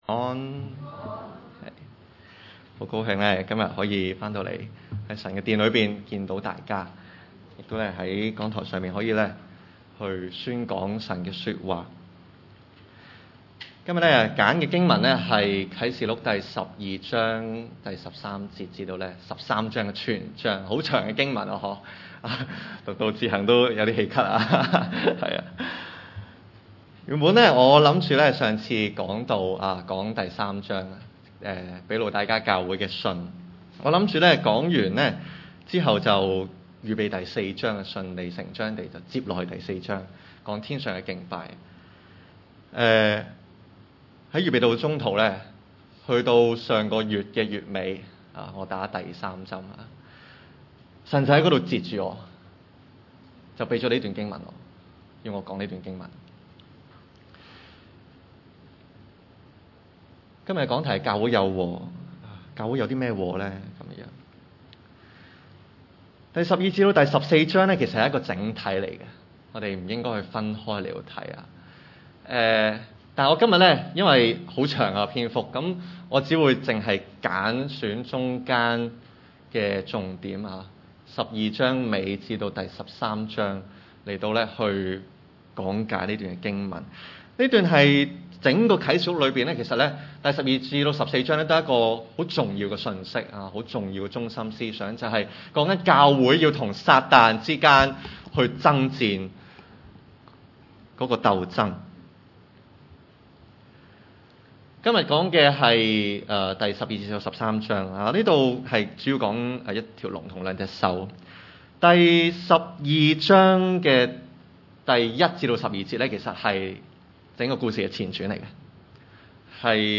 經文: 啟示錄 12:13 – 13:18 崇拜類別: 主日午堂崇拜 13.